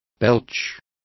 Also find out how eructos is pronounced correctly.